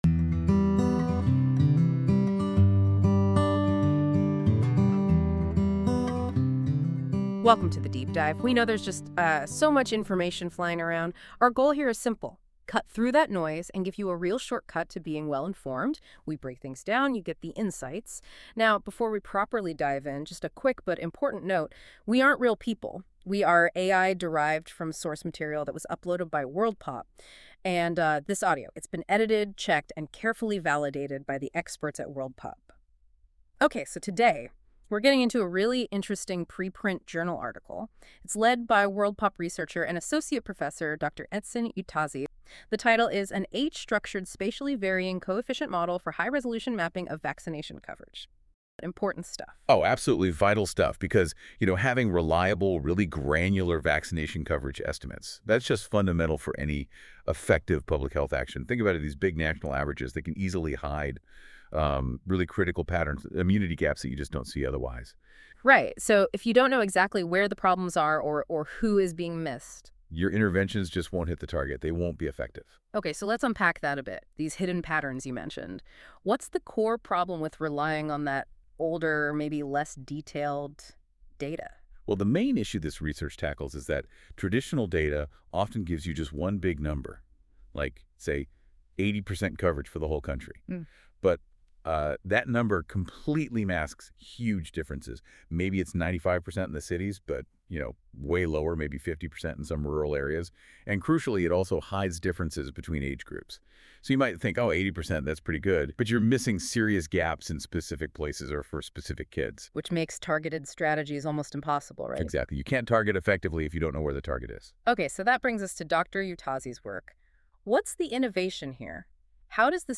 An Age-Structured Spatially Varying Coefficient Model - audio summary
This feature uses AI to create a podcast-like audio conversation between two AI-derived hosts that summarise key points of a document - in this case the Disappearing People article in Science.
Music: My Guitar, Lowtone Music, Free Music Archive (CC BY-NC-ND)